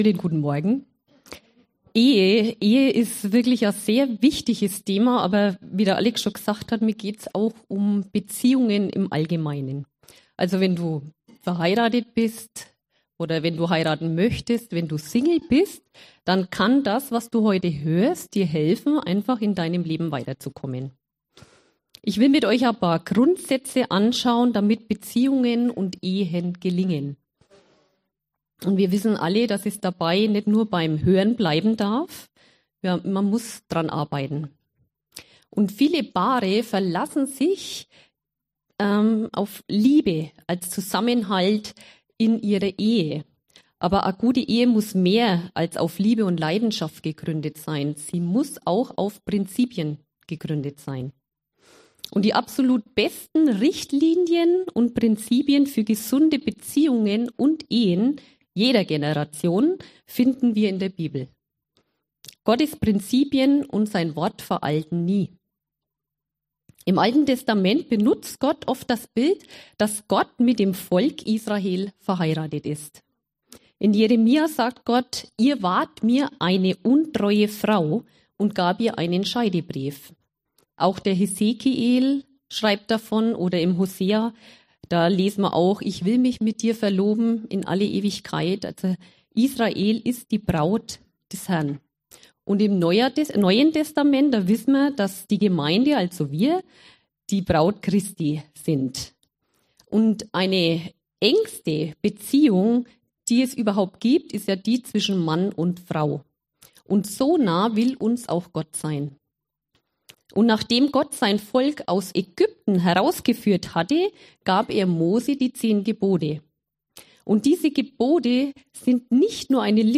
Wie hat dir diese Predigt gefallen?